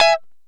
Lng Gtr Chik Min 09-C#3.wav